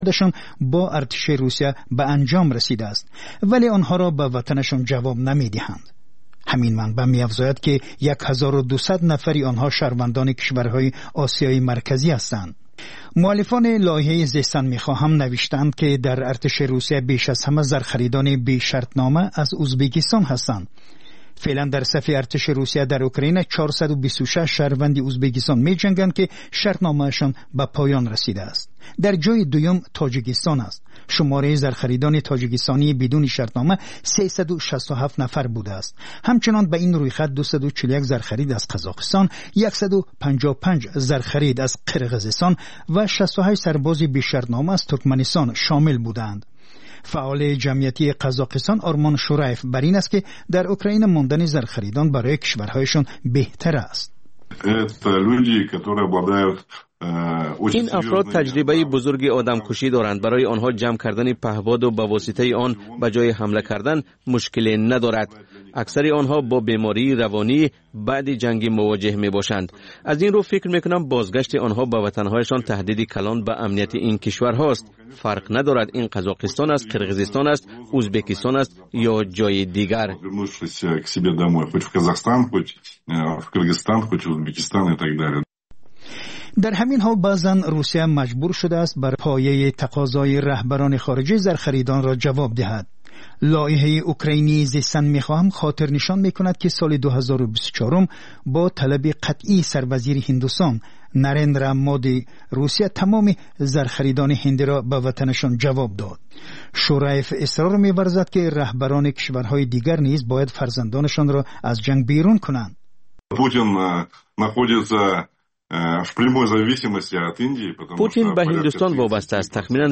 Маҷаллаи хабарӣ